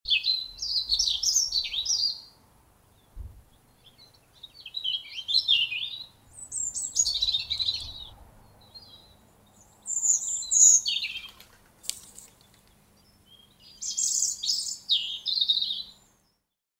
Stimme Rotkehlchen
Rotkehlchen.mp3